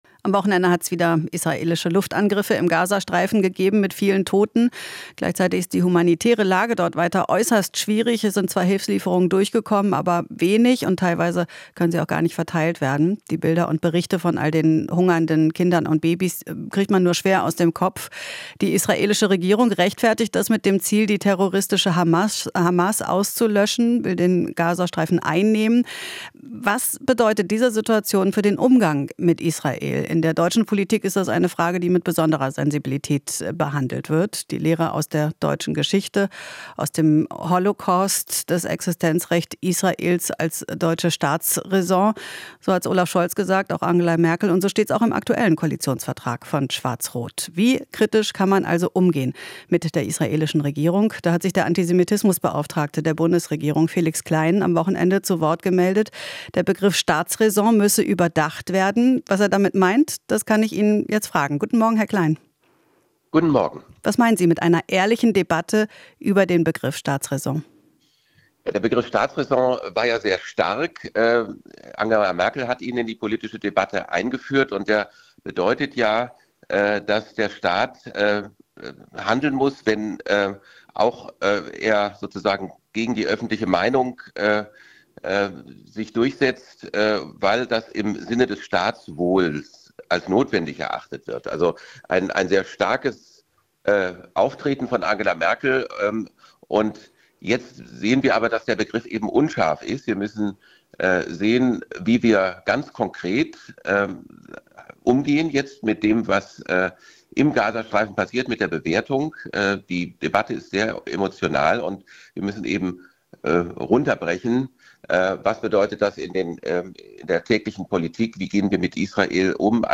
Interview - Antisemitismus-Beauftragter Klein: Mit Israel reden, nicht sanktionieren